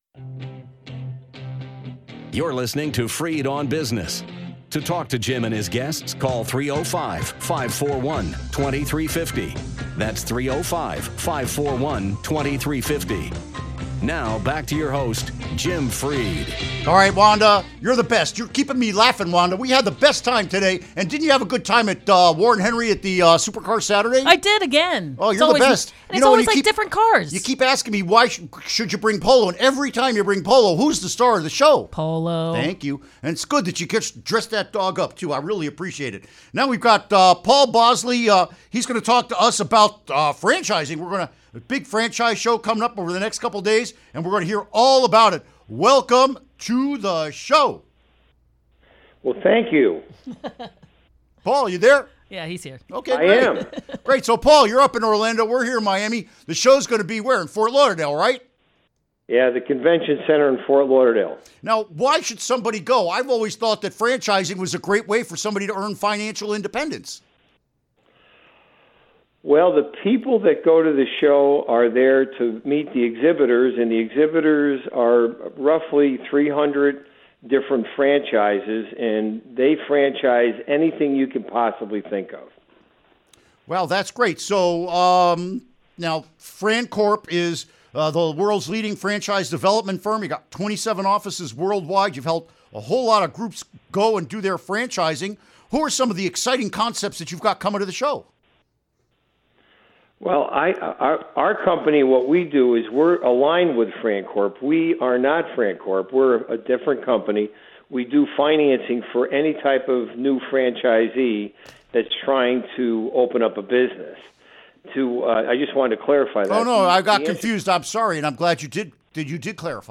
Interview Segment Click here to download (To download, right-click and select “Save Link As”.)